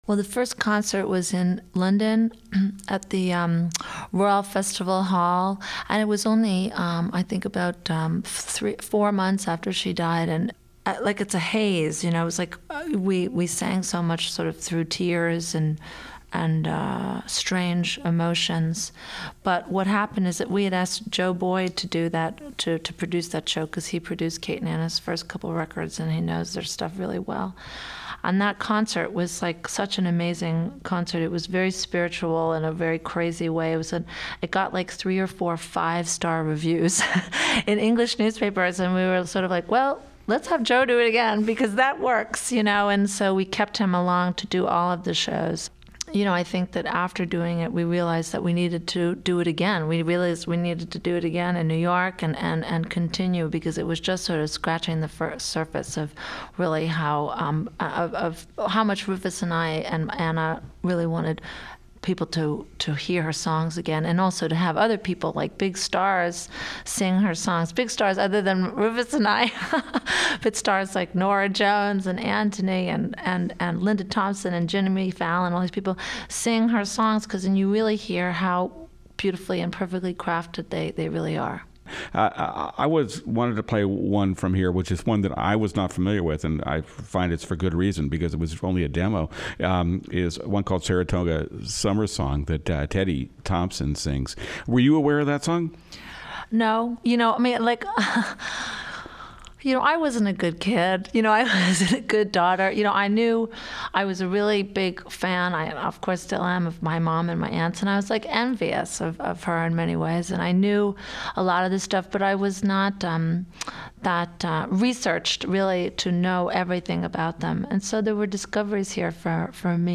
Here, Martha Wainwright joins David Dye to revisit the tribute concerts held in the wake of Kate McGarrigle's death.